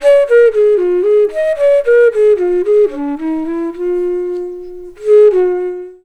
FLUTE-B10 -R.wav